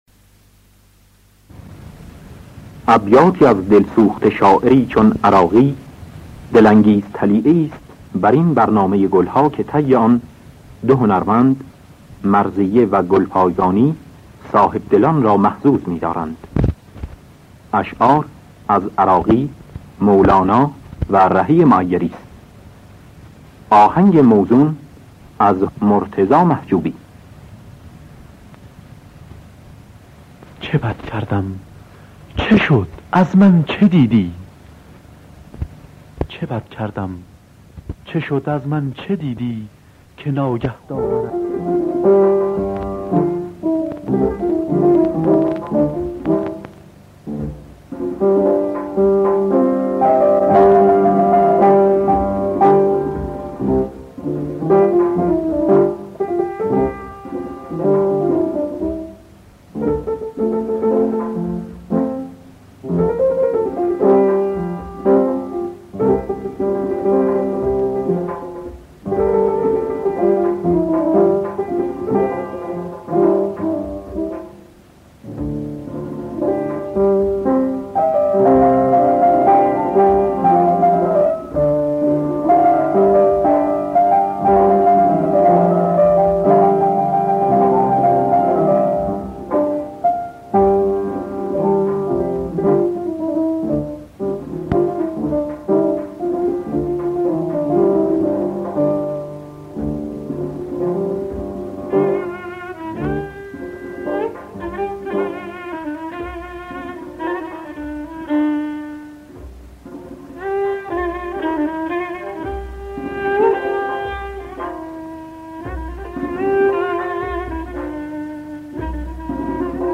گلهای رنگارنگ ۲۲۷ - ابوعطا
خوانندگان: مرضیه اکبر گلپایگانی نوازندگان